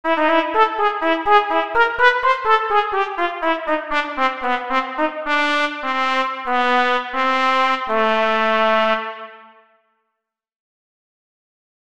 Fraseo de trompeta